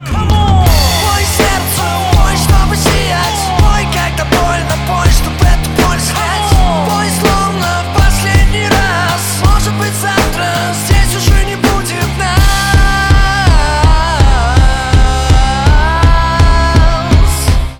• Качество: 128, Stereo
громкие
брутальные
Хип-хоп
Cover
Рэп-рок